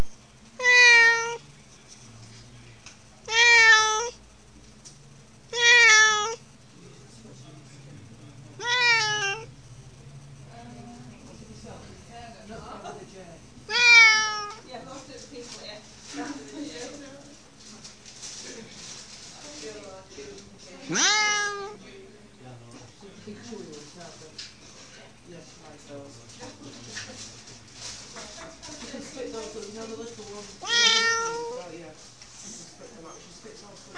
unhappy eddie at the vet